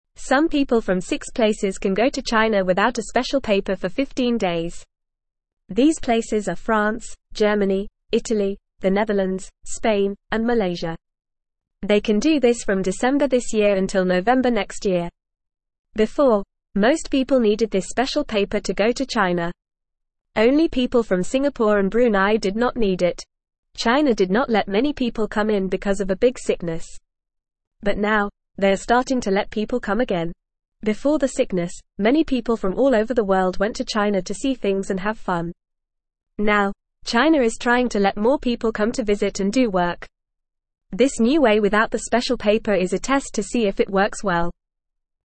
Fast
English-Newsroom-Beginner-FAST-Reading-China-Allows-Visitors-from-Six-Countries-Without-Special-Paper.mp3